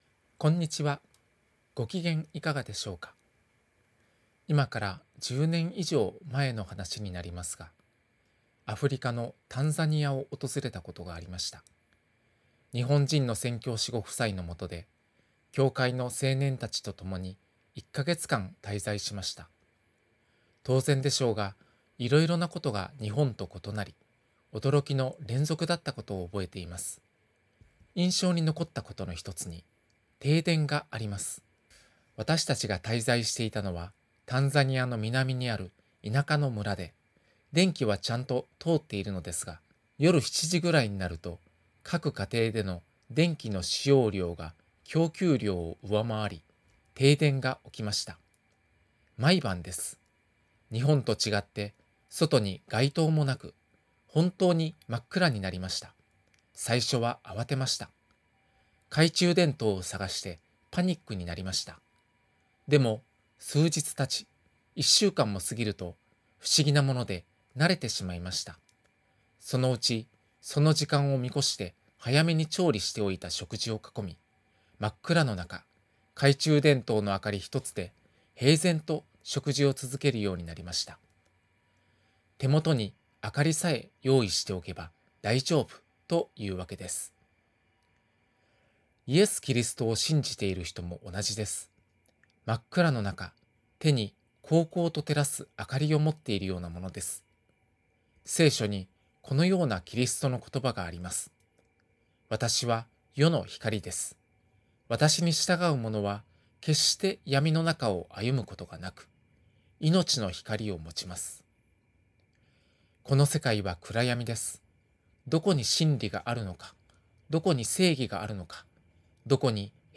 電話で約３分間のテレフォンメッセージを聞くことができます。